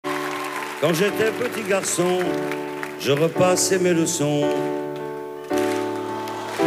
DUOS